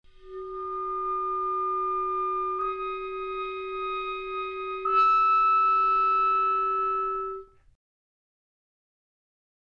Multiphonic Sequences
A number of multiphonic sequences are quite easy to produce in legato articulation.